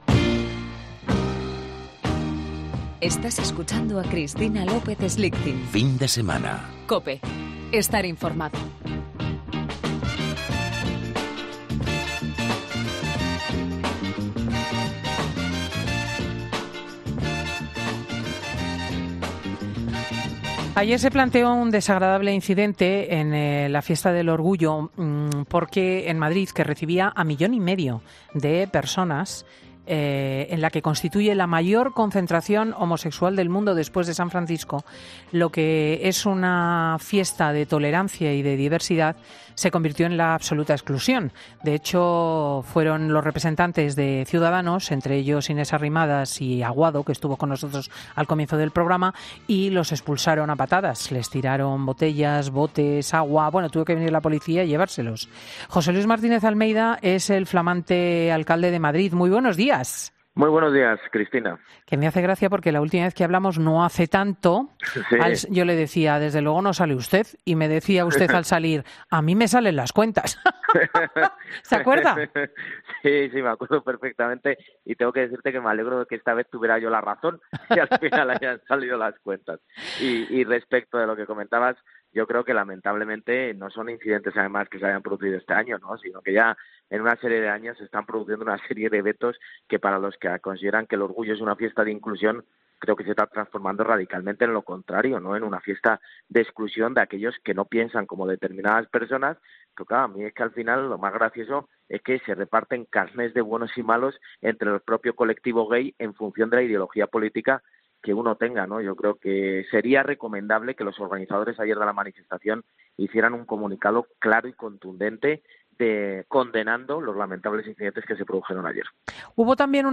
El alcalde de la capital, José Luis Martínez-Almeida en 'Fin de Semana'